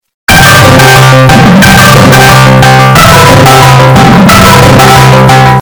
Discord Call Way Too Bass Boosted Sound Effect Download: Instant Soundboard Button